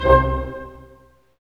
Index of /90_sSampleCDs/Roland L-CD702/VOL-1/HIT_Dynamic Orch/HIT_Staccato Oct